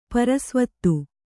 ♪ para svattu